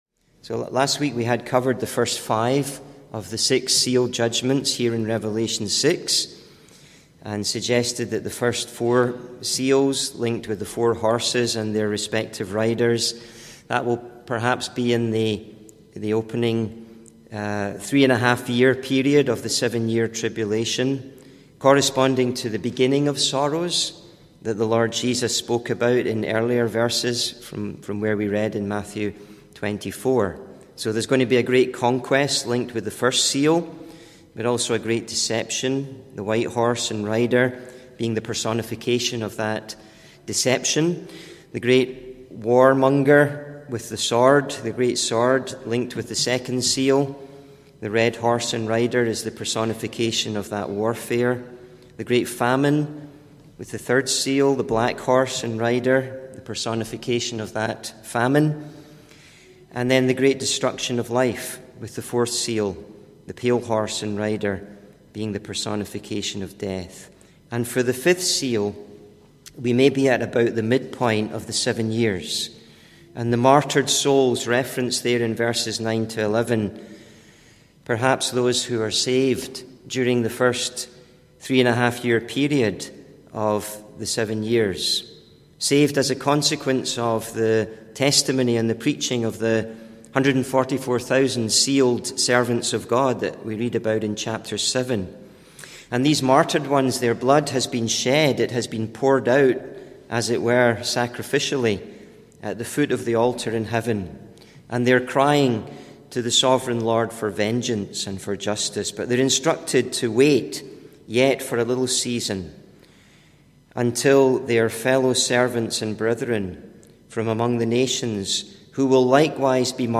(Recorded in Parkview Street Gospel Hall, Winnipeg, MB, Canada on 20th Oct 2024)
(Recorded in Parkview Street Gospel Hall, Winnipeg, MB, Canada on 20th Oct 2024) Sermon series: The 7-Sealed Scroll The 4 Horsemen of the Apocalypse The Wrath of the Lamb